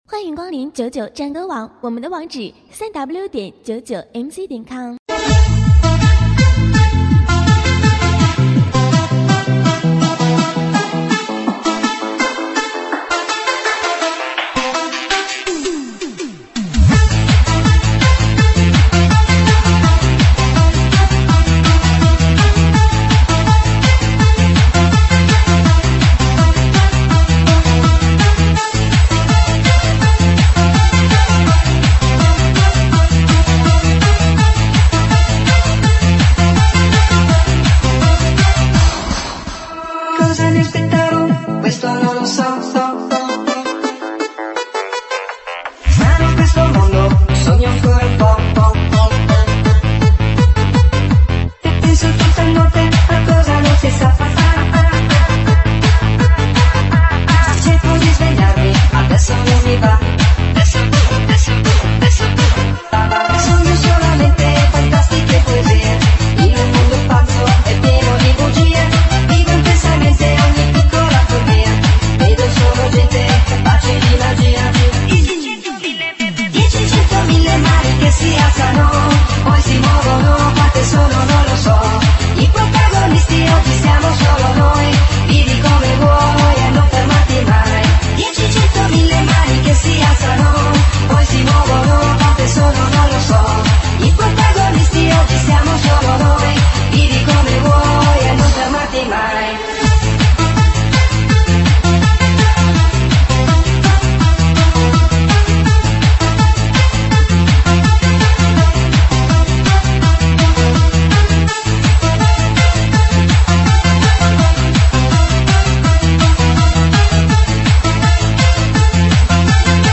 英文舞曲